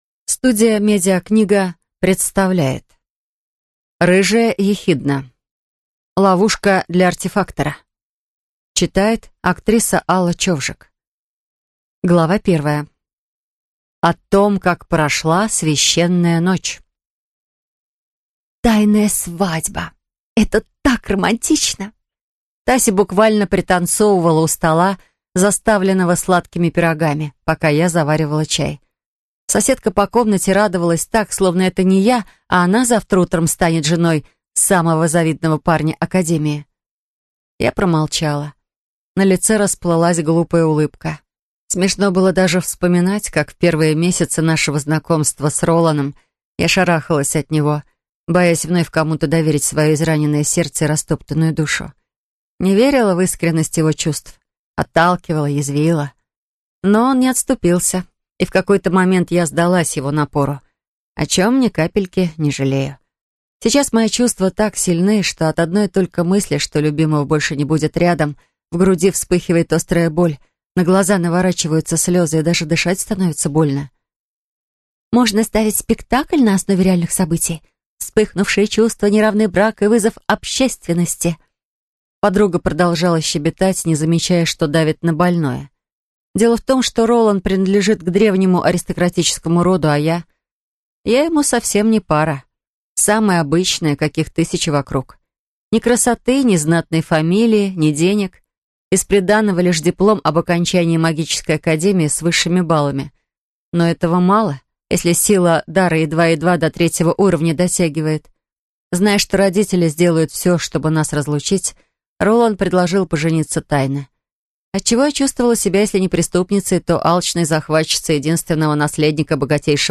Аудиокнига Ловушка для артефактора | Библиотека аудиокниг